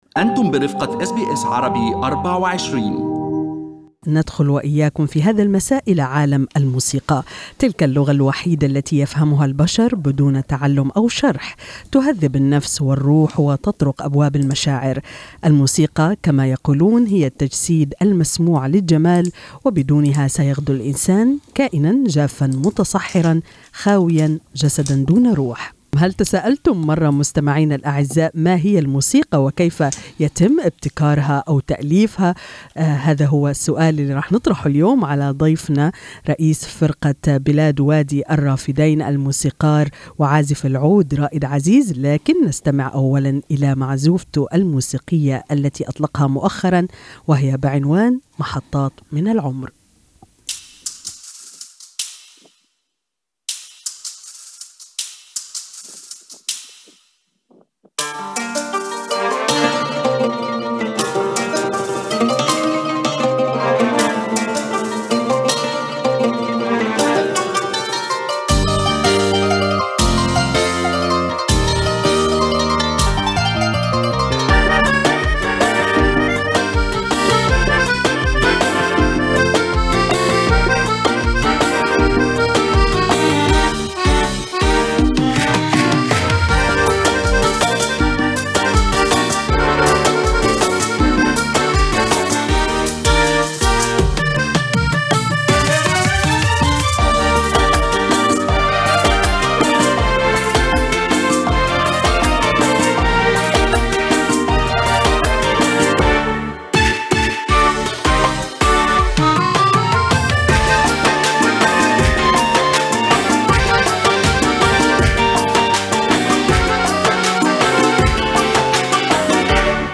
استمعوا الى للقاء الكامل مع الموسيقار وعازف العود